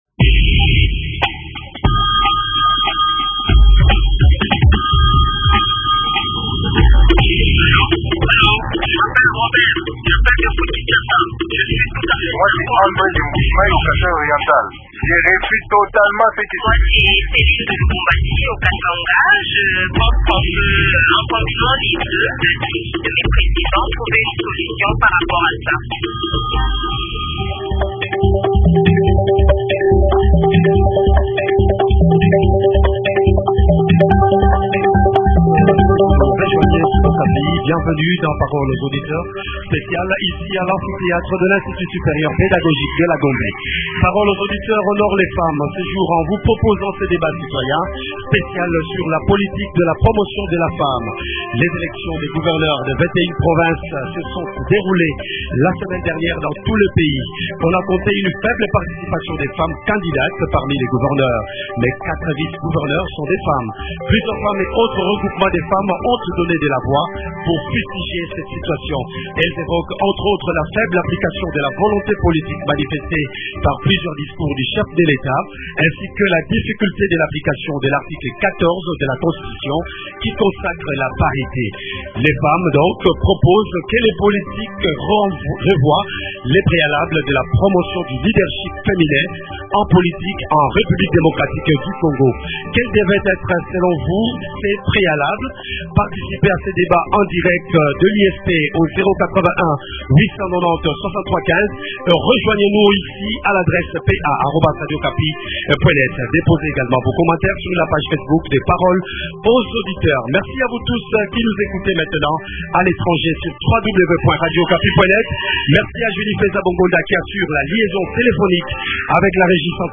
En marge de la clôture du mois de la femme, Parole aux Auditeurs se déplace pour une émission spéciale en direct de l’ISP Gombe à Kinshasa.